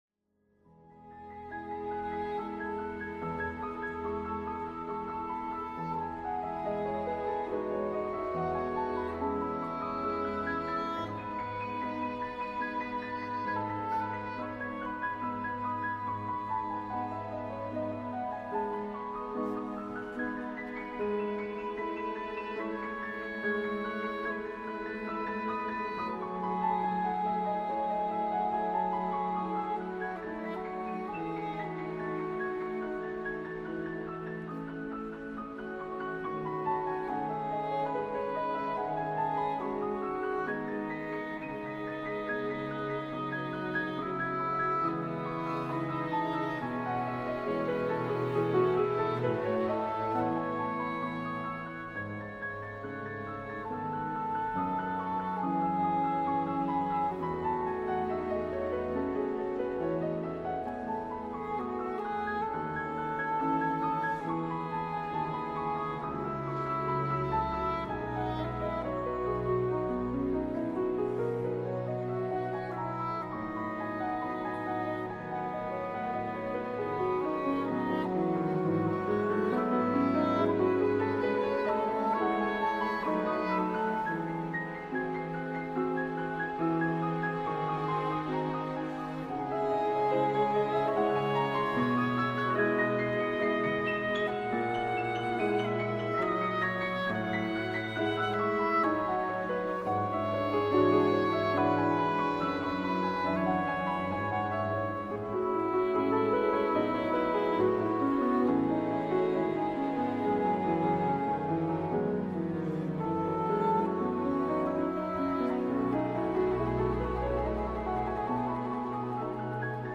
conversaron sobre libros y música con el gran novelista francés Jean Echenoz, en el marco del FILBA, en su edición montevideana 2017.